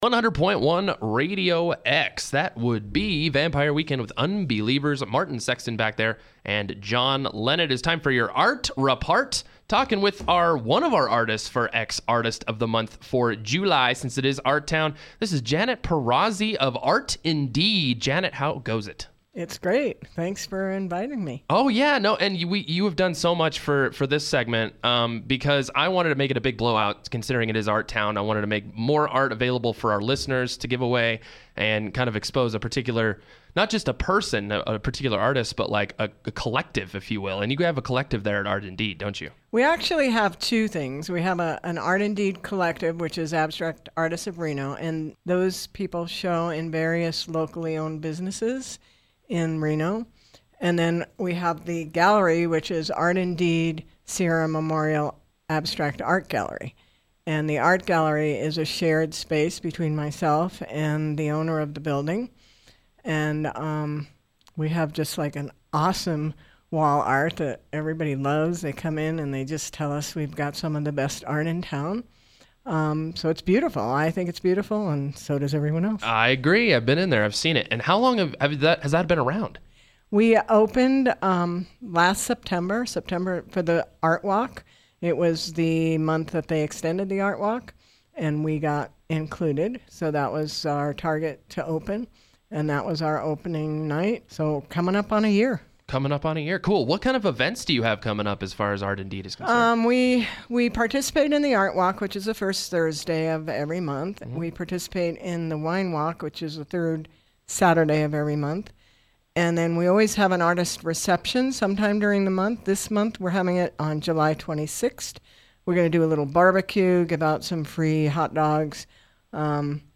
July X Artist Interview